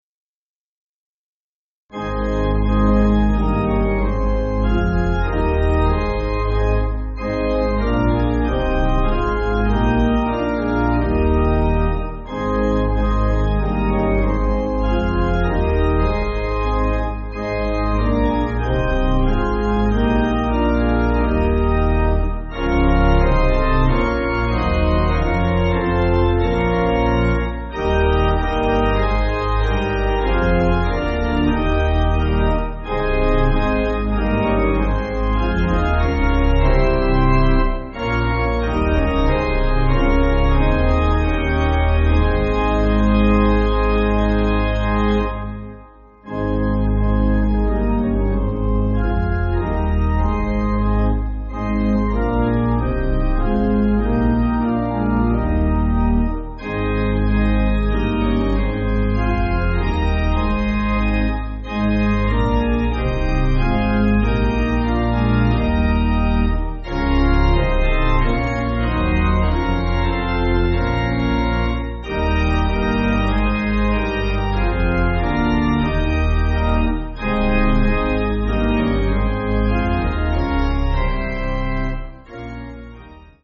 Organ
(CM)   4/Ab